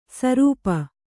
♪ sarūpa